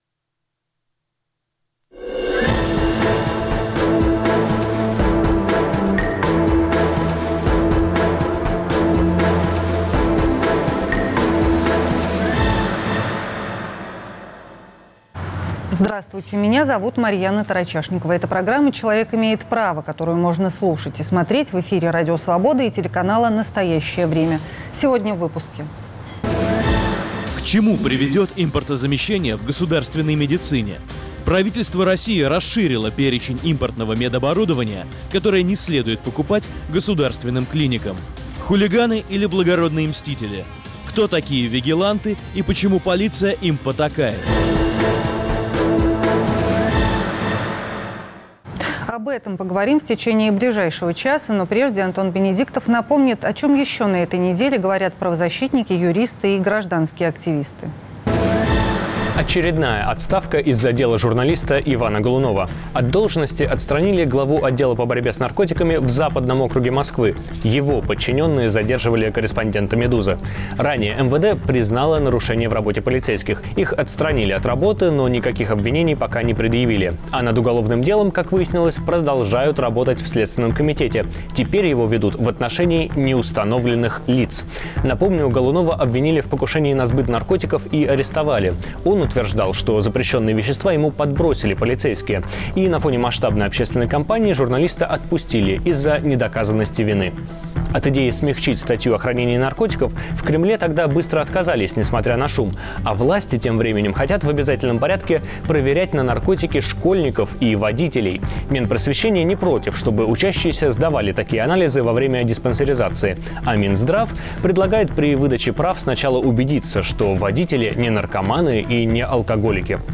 юристы, правозащитники, гражданские активисты рассказывают о том, как должно быть по закону и, что нужно делать, чтобы закон работал на вас.